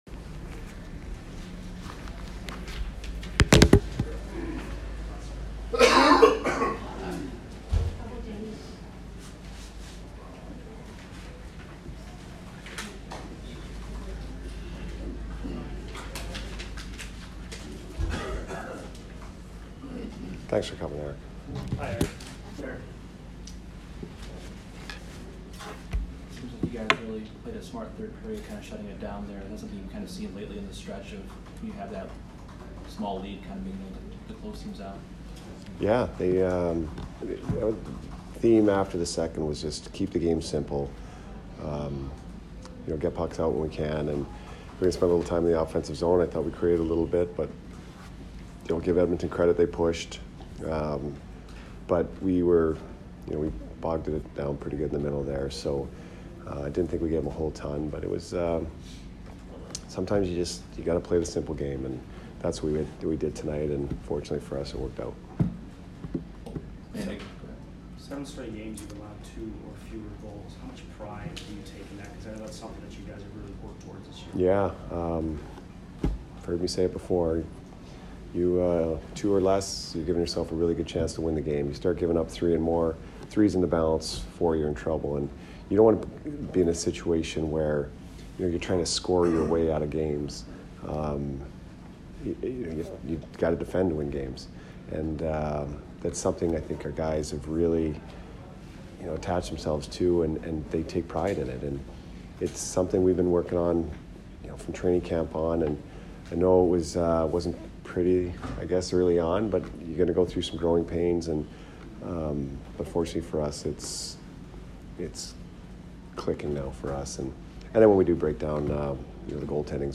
Jon Cooper post-game 2/13